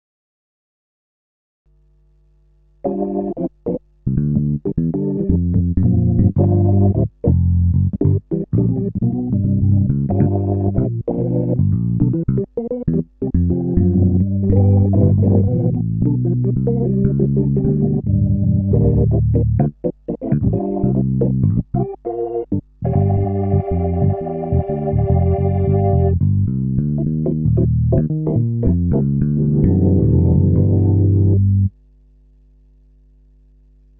Where is that warm, cool *Blues* sound ?
To start of a (possible) discussion, here's a little "corrected PC3 sounds" live played example I did in two unprocessed, analog output recorded tracks, neutrally mixed together:
pc3corr_orgbass.mp3